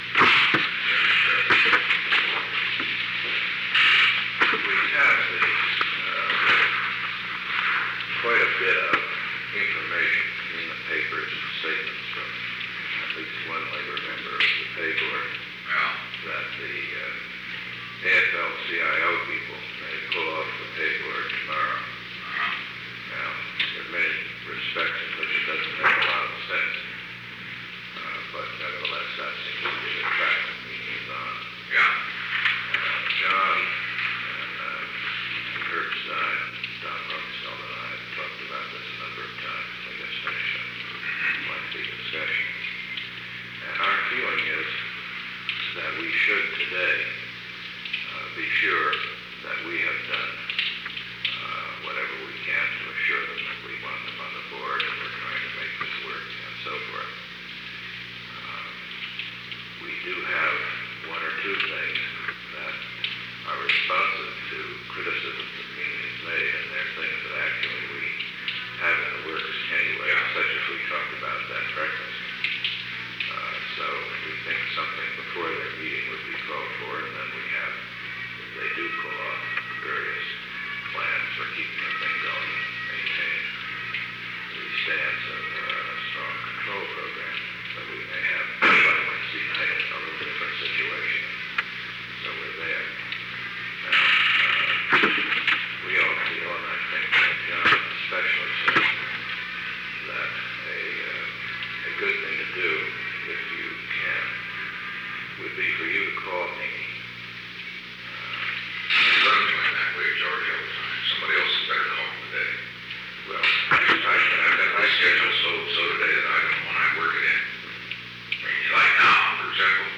Recording Device: Oval Office
The Oval Office taping system captured this recording, which is known as Conversation 690-007 of the White House Tapes. Nixon Library Finding Aid: Conversation No. 690-7 Date: March 21, 1972 Time: 10:07 am - 10:34 am Location: Oval Office The President met with George P. Shultz.